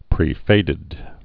(prē-fādĭd)